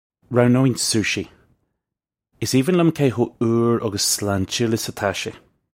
Pronunciation for how to say
Row-noe-in sushi. Iss even lyum kay kho oor uggus slawn-chool iss a-taw shay
This comes straight from our Bitesize Irish online course of Bitesize lessons.